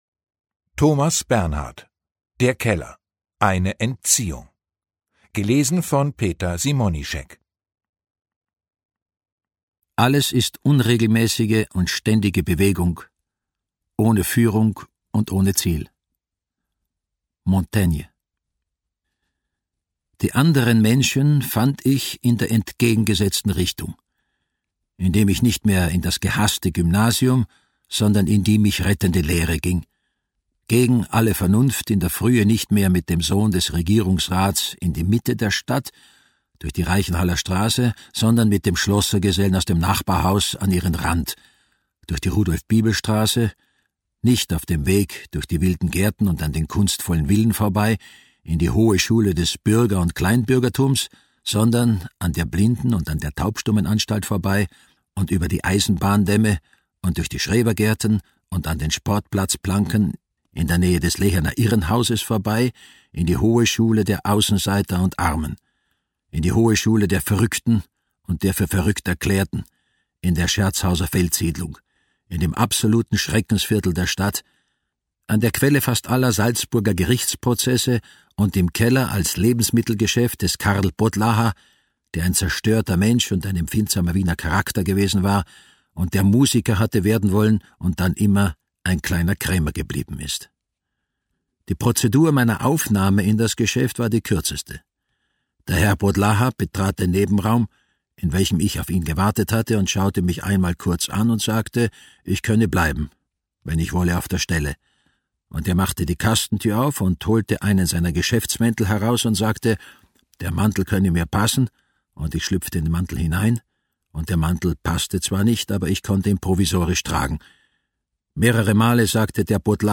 Hörbuch: Der Keller.
Der Keller. Eine Entziehung Ungekürzte Lesung mit Peter Simonischek
Peter Simonischek (Sprecher)